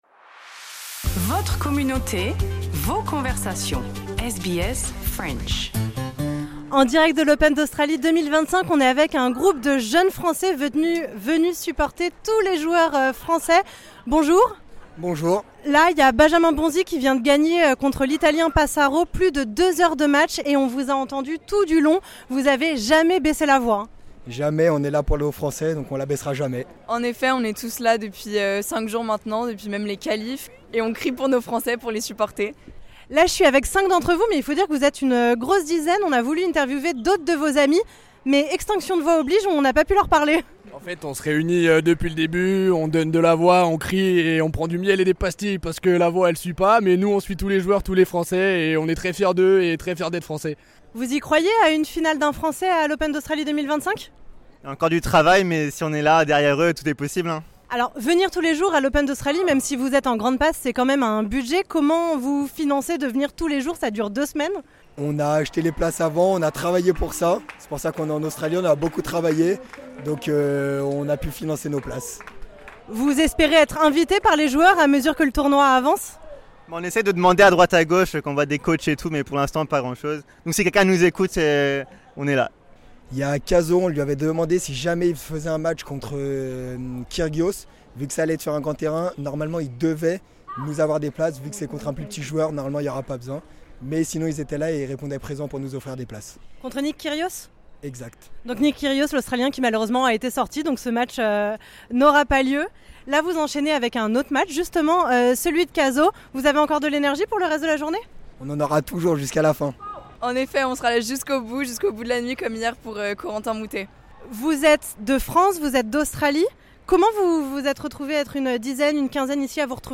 Une dizaine de jeunes français ne manquent aucun match des joueurs français et les soutiennent avec des cris et des chants, de quoi déstabiliser les adversaires. Infatigables, nous les avons rencontrés après la victoire de Benjamin Bonzi au deuxième tour du tournoi de tennis.